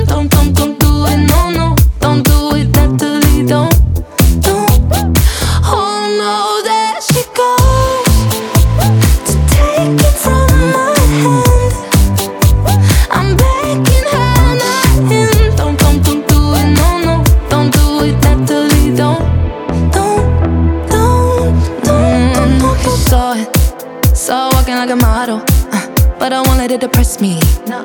То есть это как бы даблер на вокале, но второй голос идет с искажениями типа дисторшена, хриплый, он шершавый и чуть сдвинут , из-за этого вокал получается плотным и пробивным.